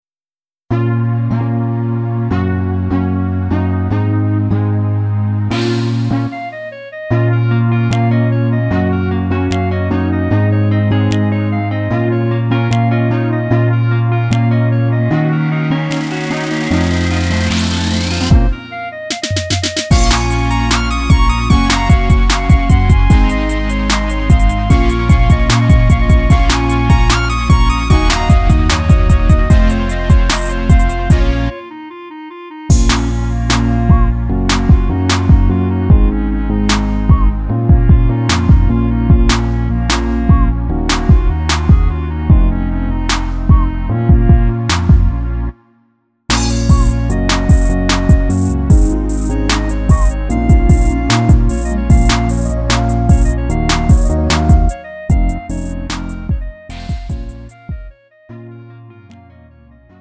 음정 -1키 3:27
장르 구분 Lite MR